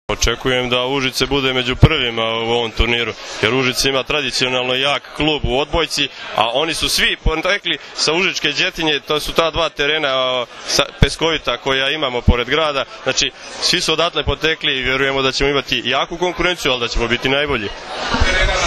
U beogradskom restoranu „Dijagonala” danas je svečano najavljen VI „Vip Beach Masters 2013.“ – Prvenstvo Srbije u odbojci na pesku, kao i Vip Beach Volley liga, u prisustvu uglednih gostiju, predstavnika gradova domaćina, odbojkašica, odbojkaša i predstavnika medija.
IZJAVA SAŠE MILOŠEVIĆA, GRADONAČELNIKA UŽICA